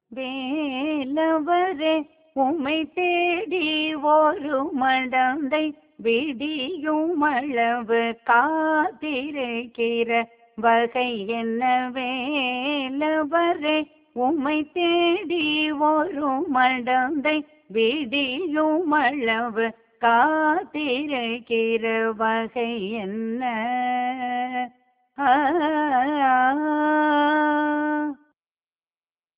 புகழ்பெற்ற இந்த பைரவி இராகப் பாடலை இயற்றினார் கனம் கிருஷ்ணய்யர்.
பைரவி...
ஆதி